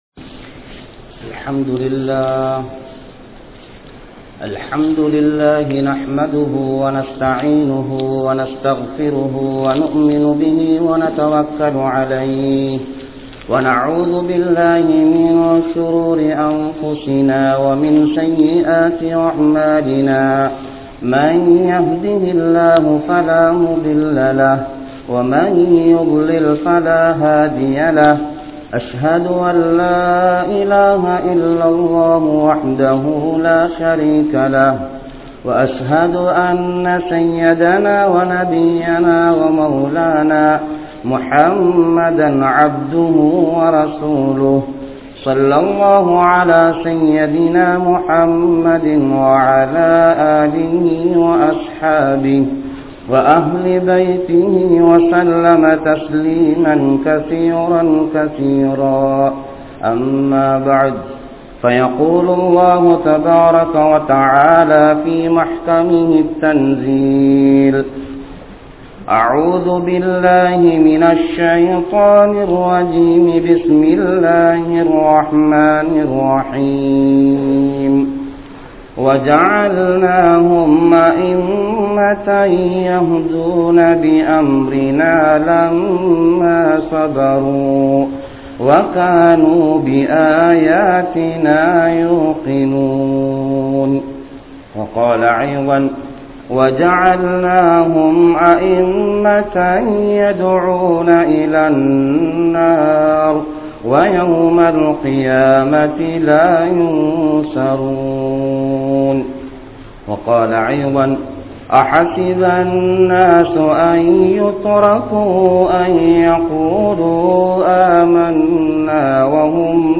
Soathanaien Vahaihal (சோதனையின் வகைகள்) | Audio Bayans | All Ceylon Muslim Youth Community | Addalaichenai